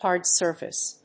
hard-surface.mp3